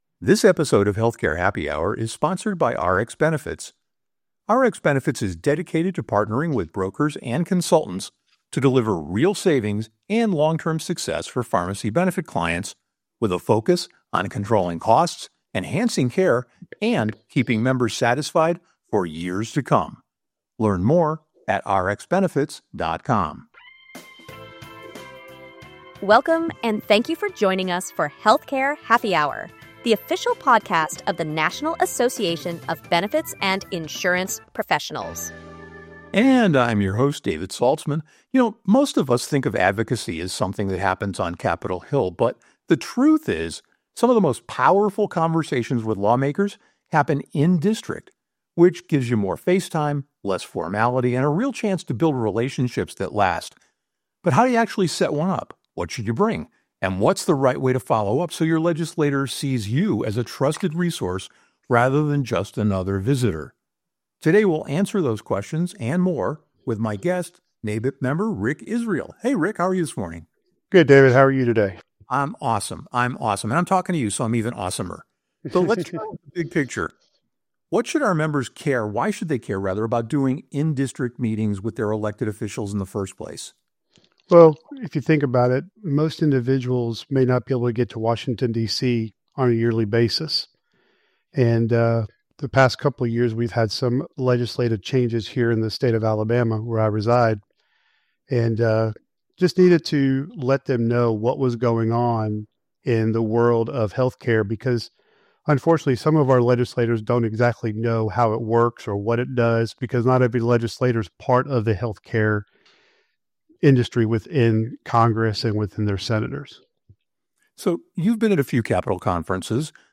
They explore strategies for scheduling these meetings, the importance of preparation, and how to build lasting relationships with legislators. The conversation also covers the engagement with state legislators and effective follow-up strategies to ensure ongoing communication and advocacy.